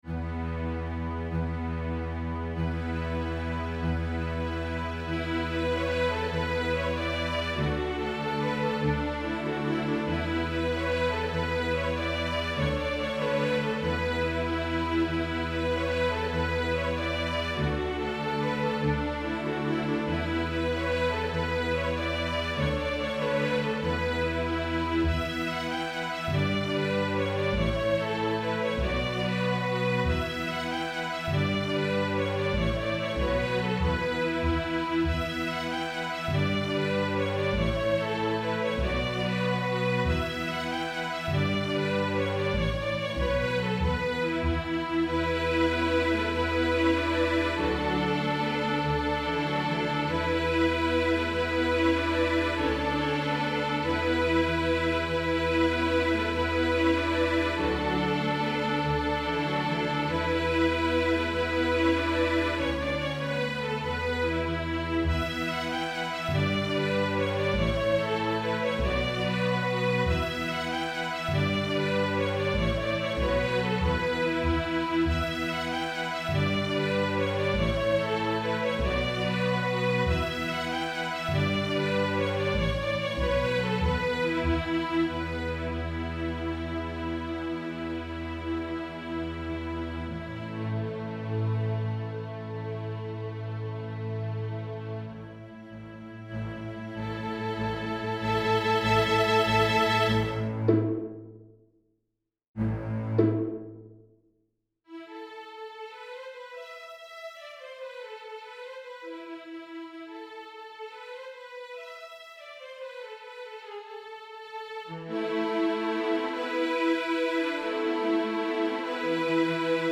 INTERMEDIATE, STRING ORCHESTRA
Notes: double stops, pizz, mordent
Key: E minor, G major